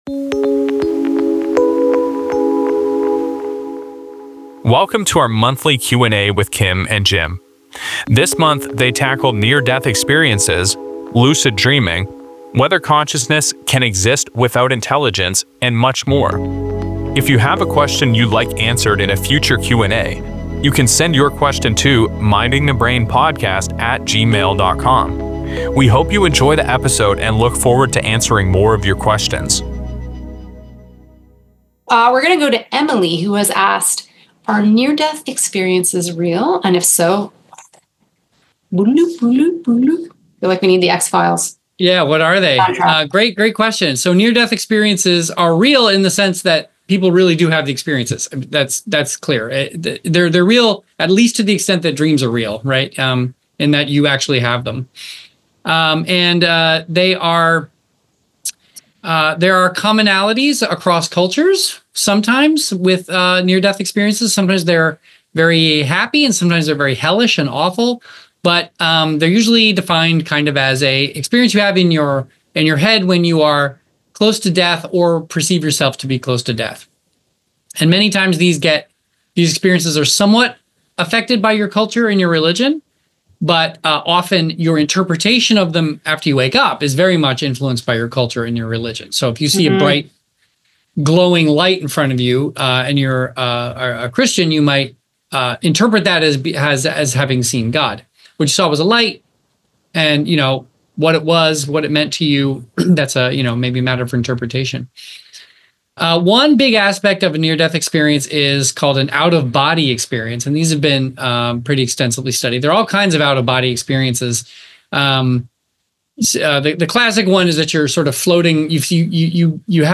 Near Death Experiences, Brain Implants & Lucid Dreaming – Q&A – #87